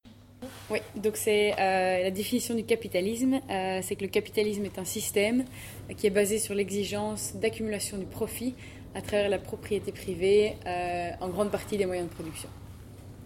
explication